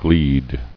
[gleed]